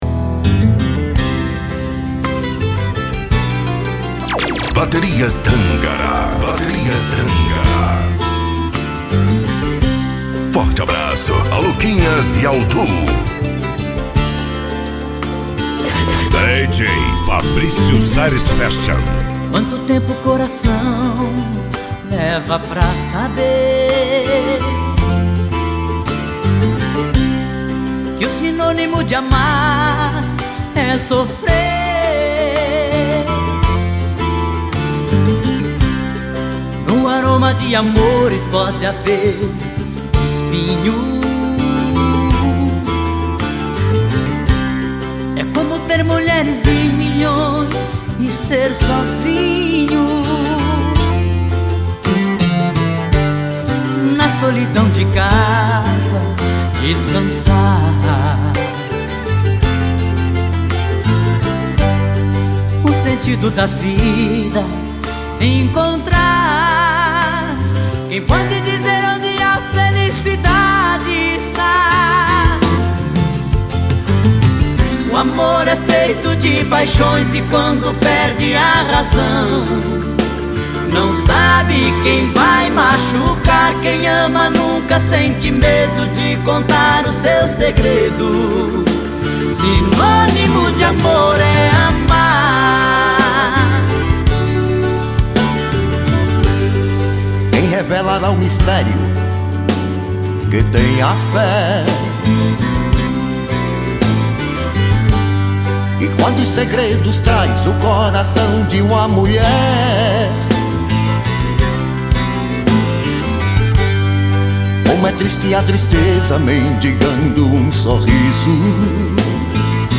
Modao